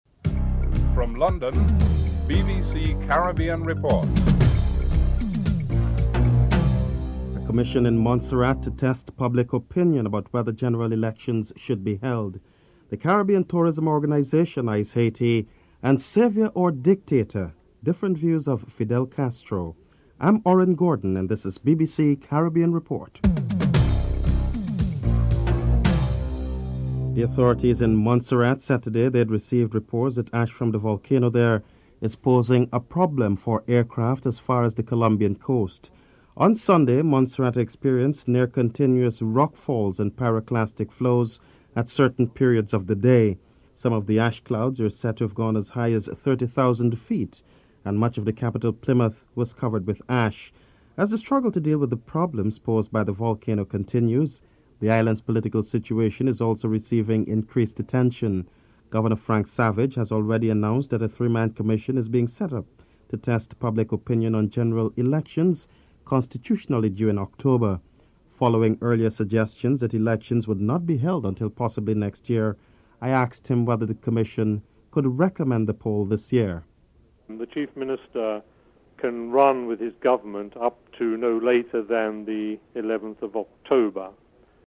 2. A commission in Montserrat to test public opinion about whether general elections should be held. Governor Frank Savage is interviewed (00:27-03:42)